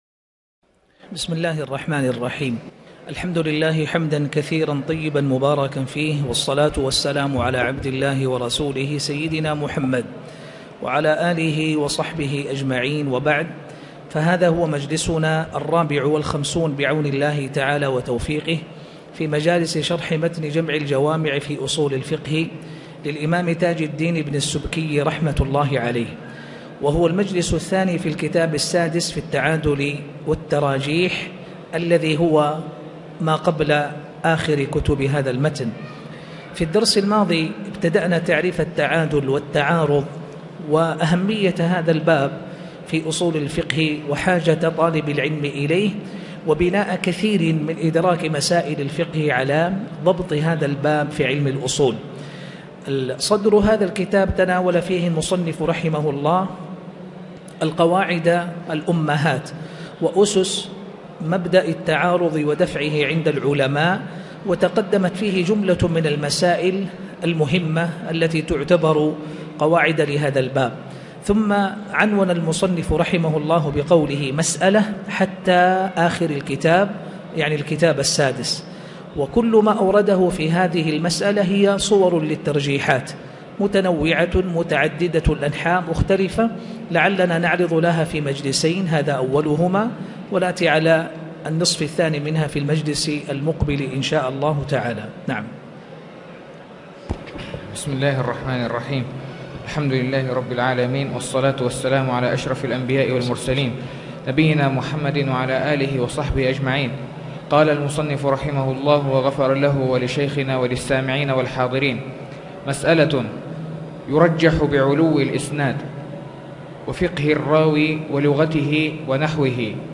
تاريخ النشر ١٨ جمادى الأولى ١٤٣٨ هـ المكان: المسجد الحرام الشيخ